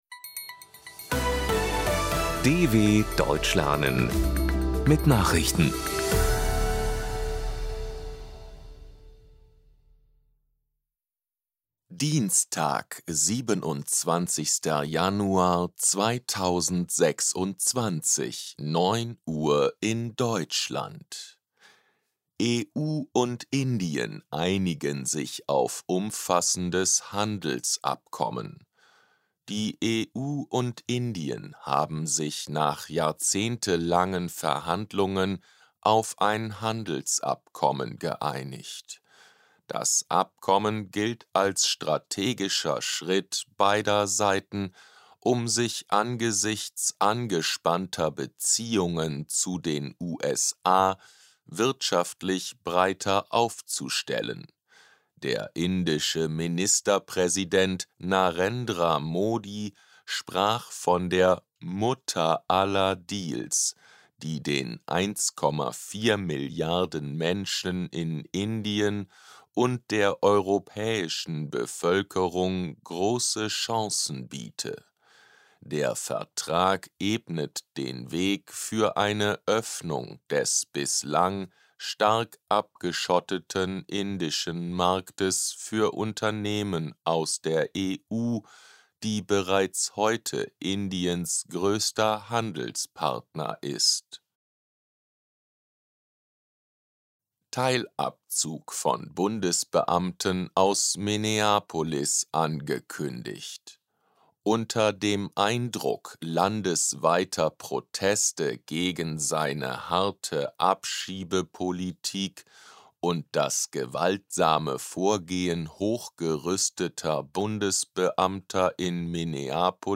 27.01.2026 – Langsam Gesprochene Nachrichten
Trainiere dein Hörverstehen mit den Nachrichten der DW von Dienstag – als Text und als verständlich gesprochene Audio-Datei.